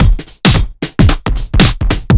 OTG_TripSwingMixD_110a.wav